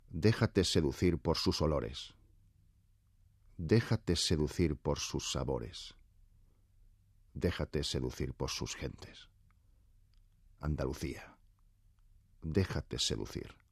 Voz masculina grave con mucha personalidad.
Sprechprobe: Werbung (Muttersprache):
Warm and masculine voice, very versatile both for dramatic and comedy roles.